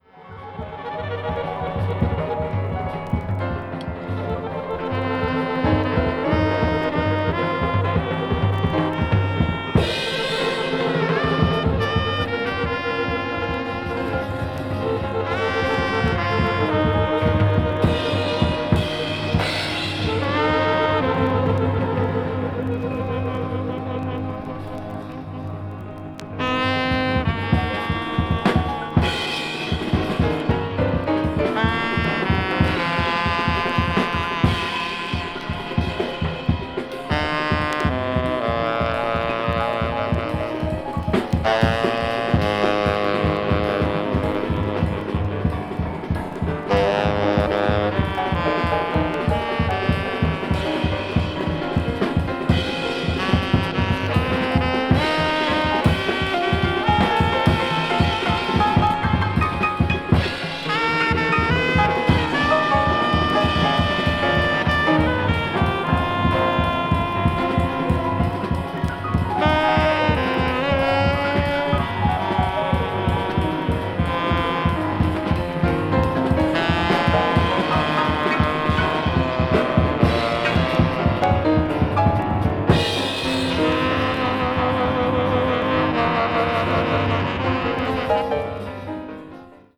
media : EX-/EX-(わずかなチリノイズが入る箇所あり,軽いプチノイズ数回あり)
avant-jazz   free improvisation   free jazz   post bop